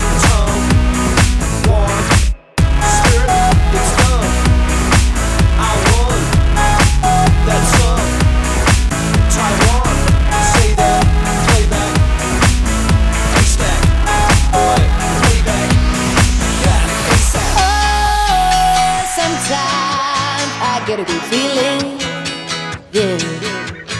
For Solo Male R'n'B / Hip Hop 4:07 Buy £1.50